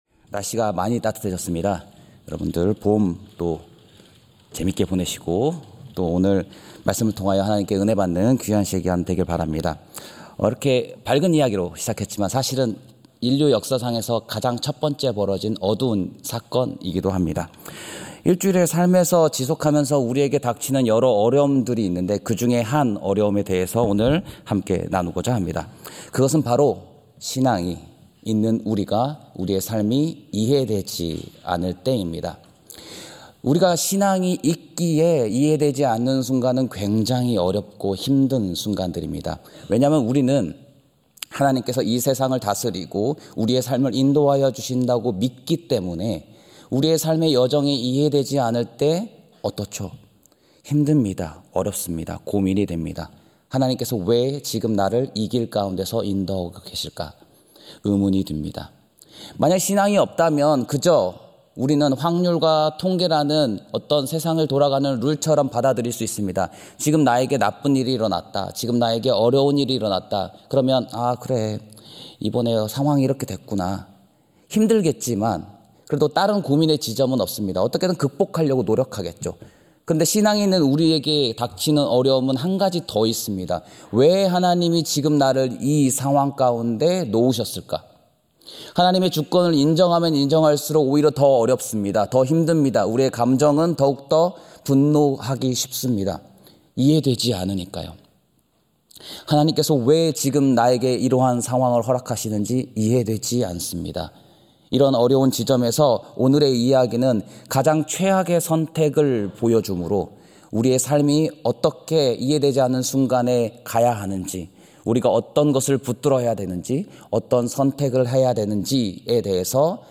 예배 수요예배